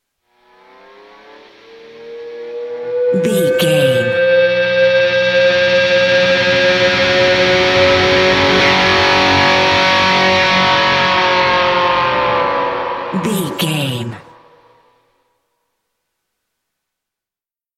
Ionian/Major
electric guitar
Slide Guitar